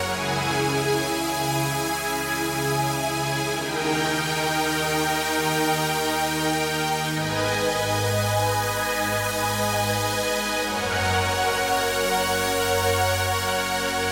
老派狂欢合成器
描述：lssic老派狂欢合成器
Tag: 136 bpm Trance Loops Synth Loops 2.38 MB wav Key : Unknown